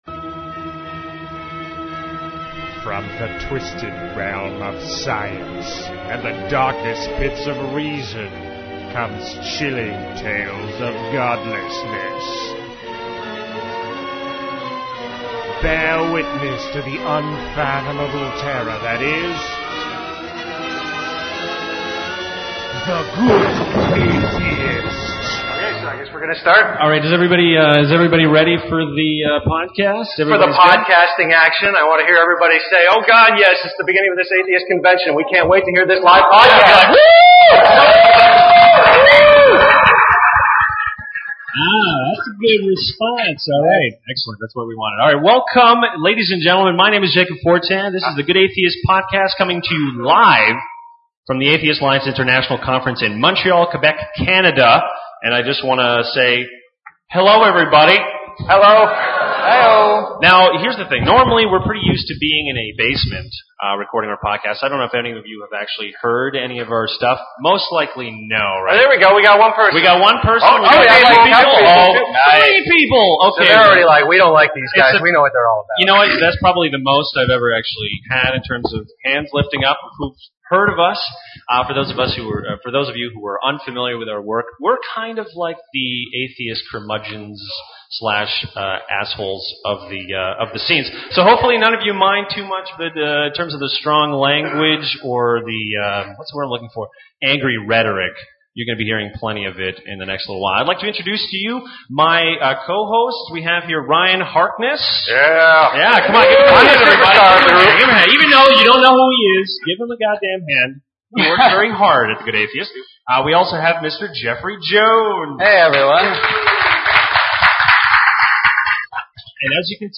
TGA’ s one and only live show, broadcasted during the American Atheist convention in Montreal, Canada.